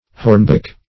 Hornbeak \Horn"beak`\, n.